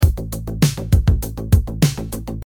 Drum-and-bass-groove-loop-100-bpm.mp3